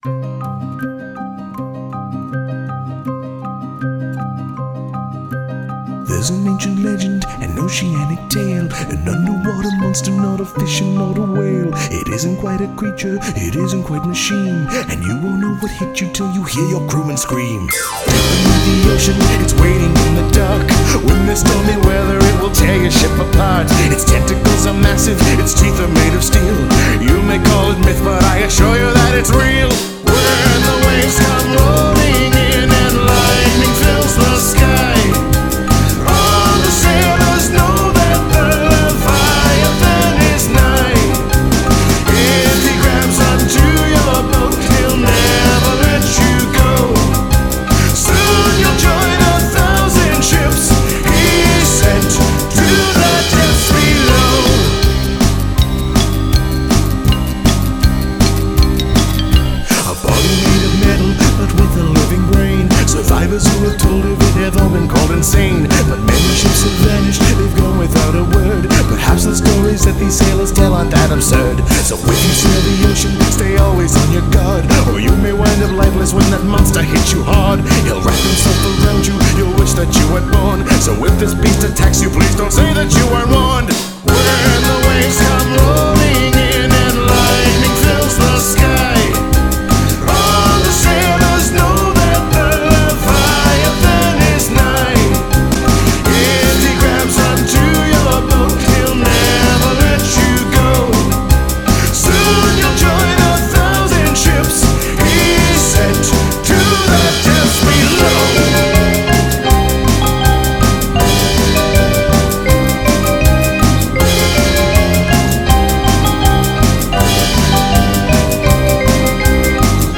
Жанр обощенно зовется стимпанк-мьюзик.